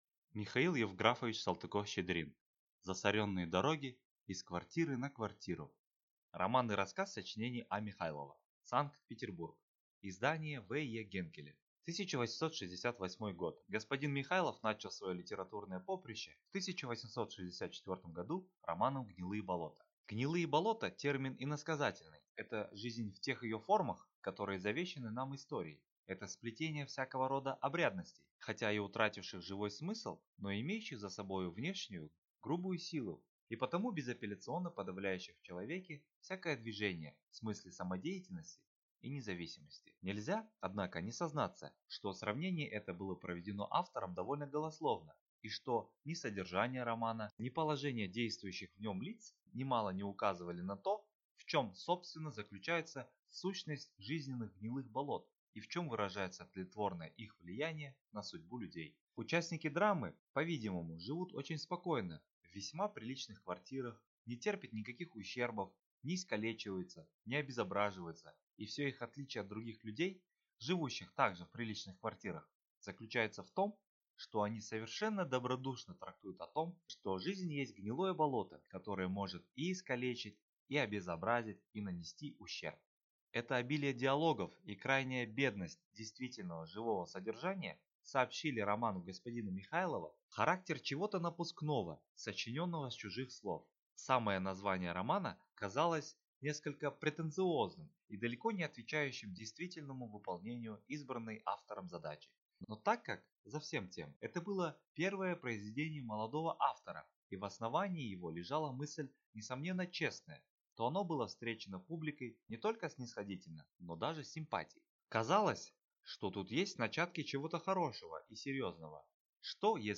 Аудиокнига Засоренные дороги и с квартиры на квартиру | Библиотека аудиокниг